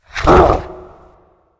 spawners_mobs_balrog_attack.2.ogg